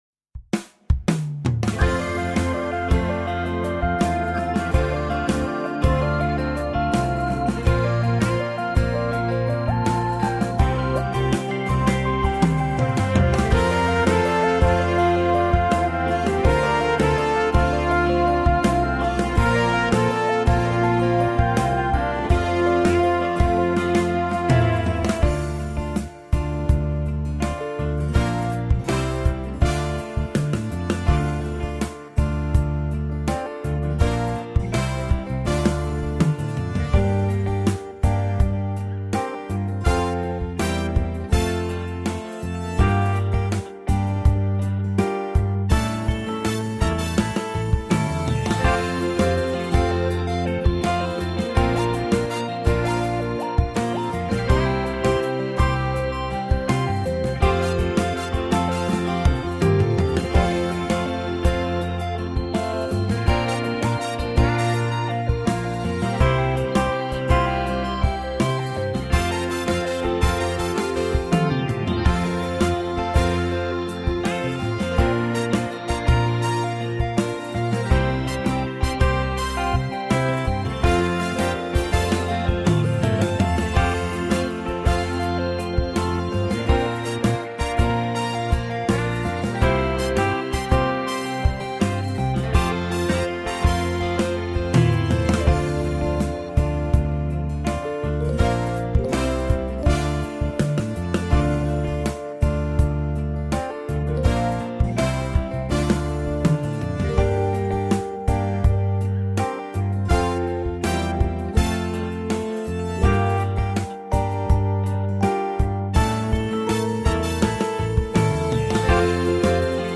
18 Cine este adevar (Minus)